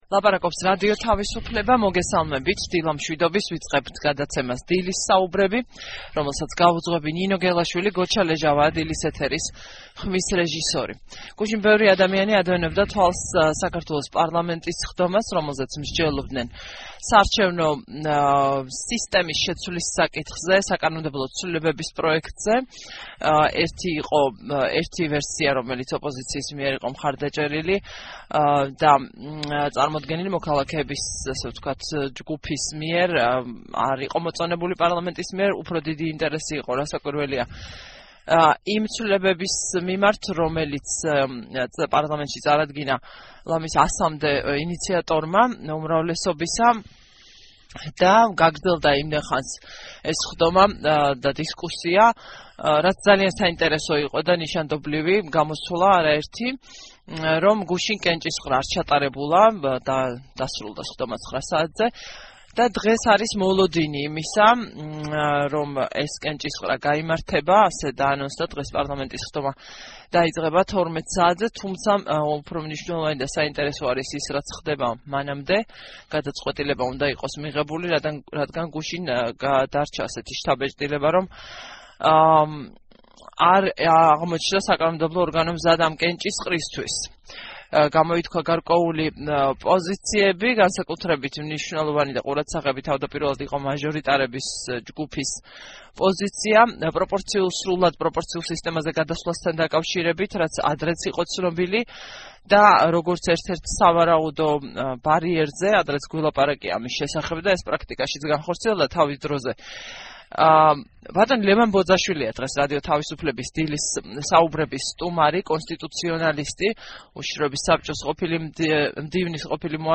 ეროვნული უშიშროების საბჭოს მდივნის ყოფილი მოადგილე რადიო თავისუფლების ეთერში ლაპარაკობს ფაქტორებზე, რაც განაპირობებს „ქართული ოცნების“ შემდგომ ნაბიჯებს საარჩევნო კანონმდებლობის ცვლილებასთან დაკავშირებულ პროცესში: